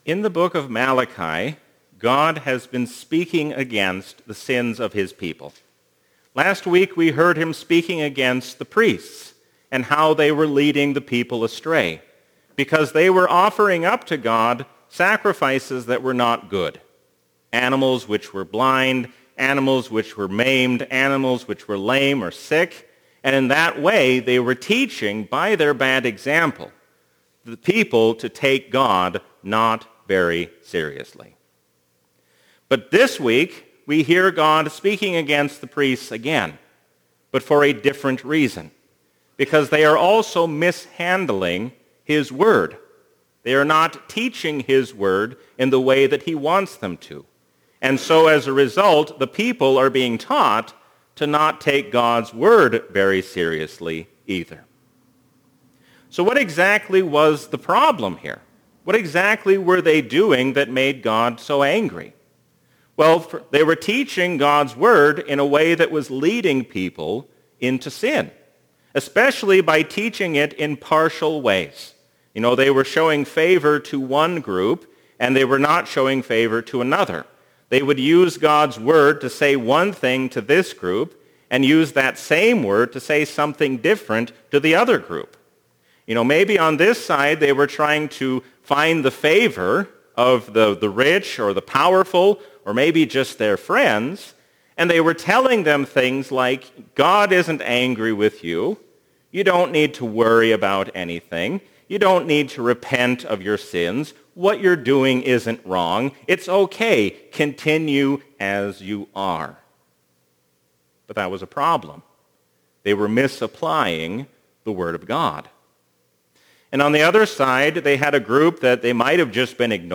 A sermon from the season "Trinity 2024." Listening to God's Word means more than just saying that it is His Word, but actually doing what it says.